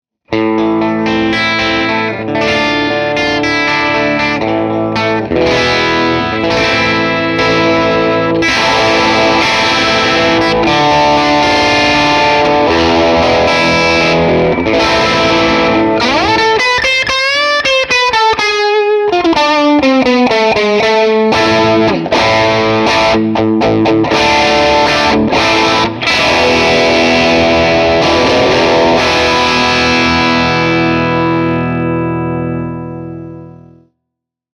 Tutte le clip audio sono state registrate con amplificatori reali iniziando con Ignition spento nei primi secondi per poi accenderlo fino alla fine della clip.
Chitarra: Fender Telecaster (pickup al ponte)
Amplificatore combo: Vox AC30 con 2 coni Celestion Greenback, canale Brilliant, volume a circa 4/10